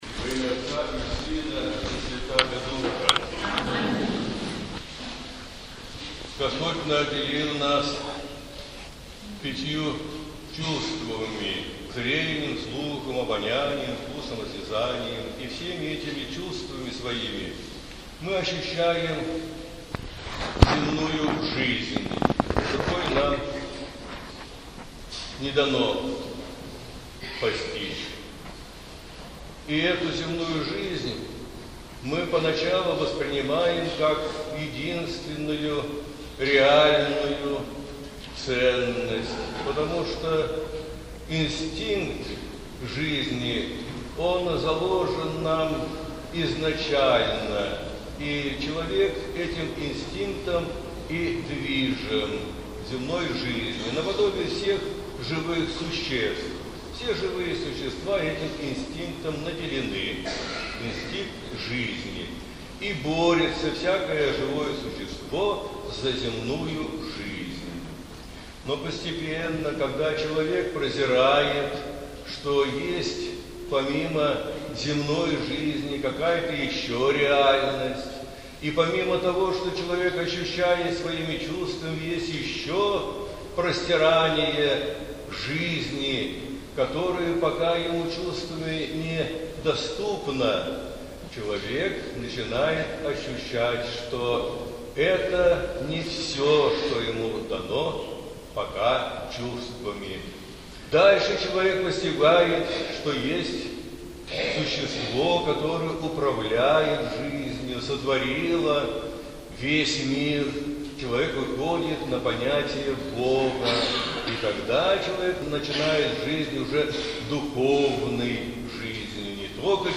Проповедь в День Победы